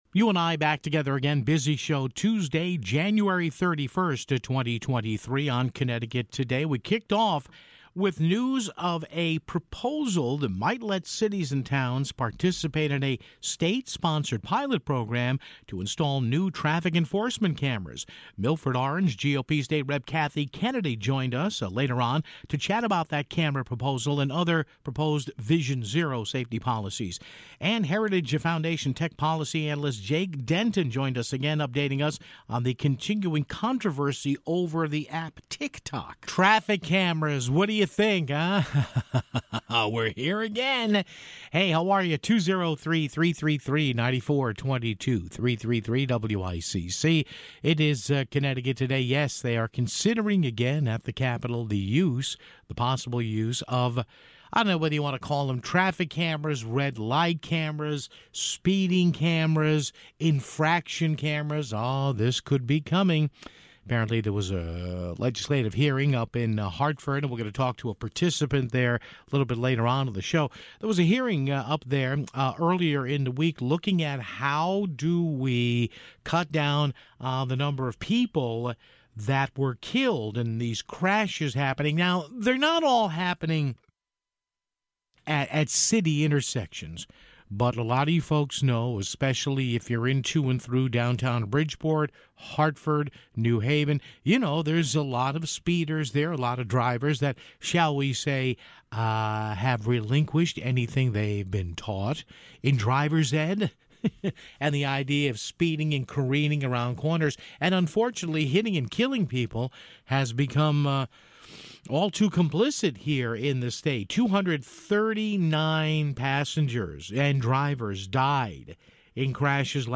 Milford/Orange GOP State Rep. Kathy Kennedy joined us to chat about that camera proposal and other proposed "Vision Zero" safety policies (11:51).